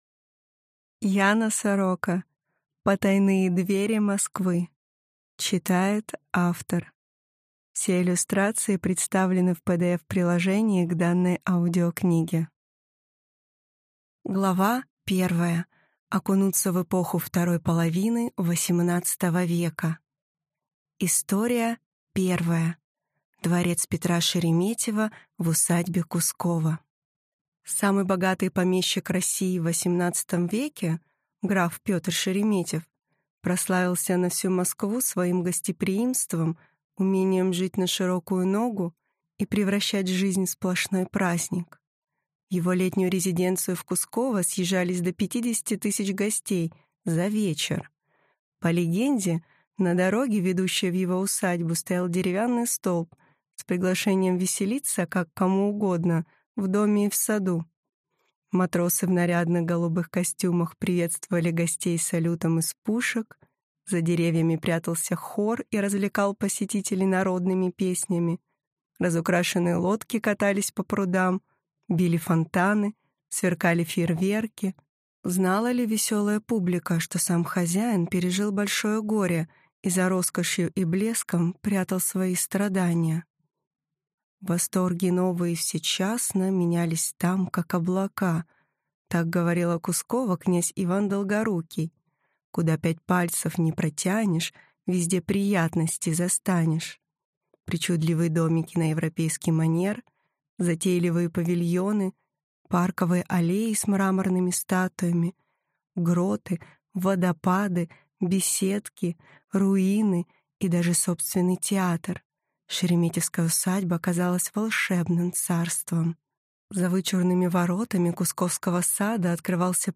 Аудиокнига Потайные двери Москвы. Старинные особняки и их истории.